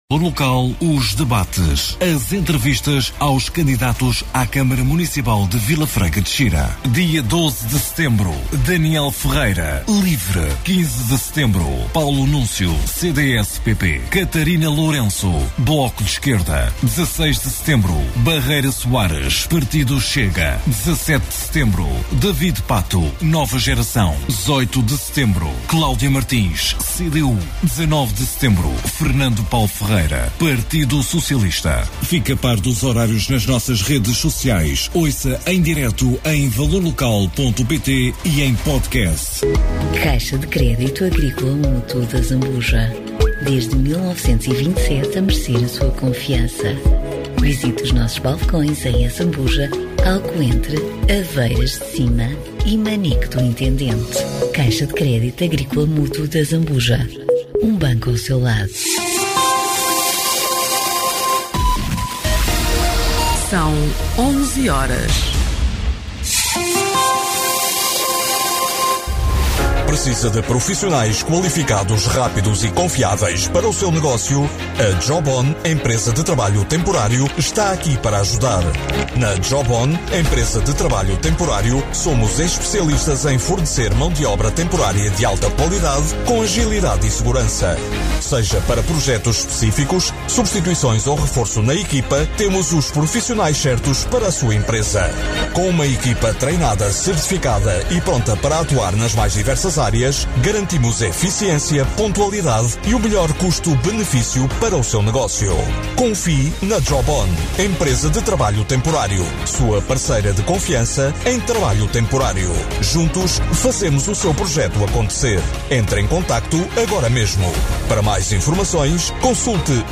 Autárquicas 2025 - Câmara Municipal Vila Franca de Xira - Entrevista a Fernando Paulo Ferreira- PS - Jornal e Rádio Valor Local Regional - Grande Lisboa, Ribatejo e Oeste